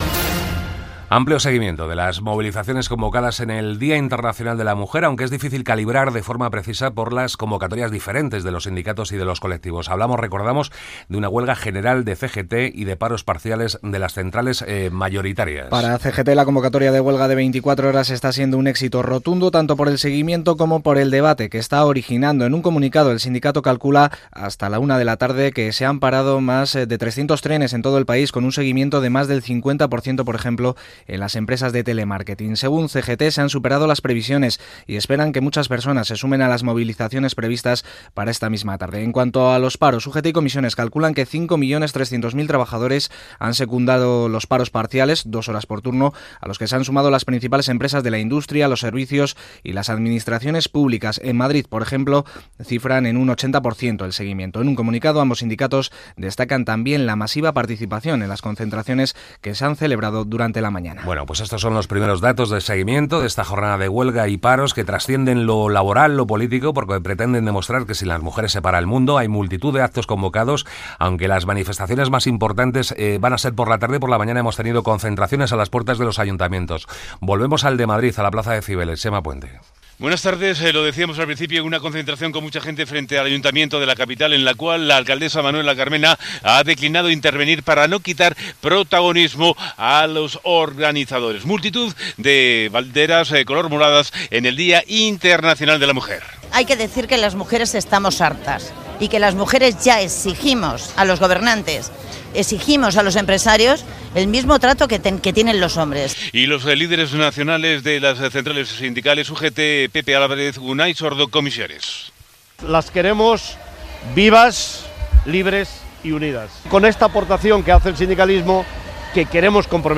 Informació des de Madrid, Barcelona i Sevilla de la vaga feminista i de les concentracions coincidnint amb el Dia Internacional de la Dona (Declaracions de les polítiques Manuela Carmena, Ada Colau i Susana Díaz). Dades sobre la discriminació de les dones
Informatiu